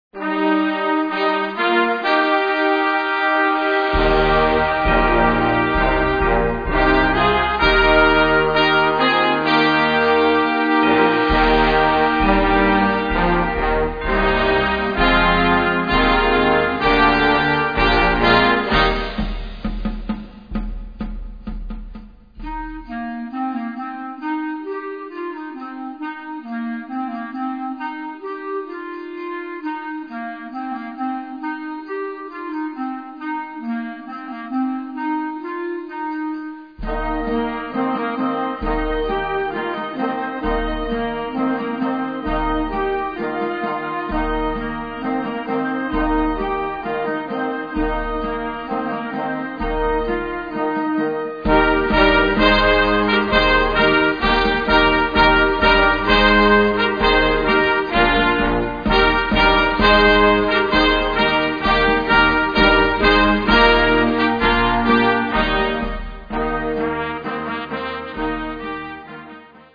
Gattung: Beginning Band
Besetzung: Blasorchester
basiert auf traditionellen Folksongtechniken und Melodien.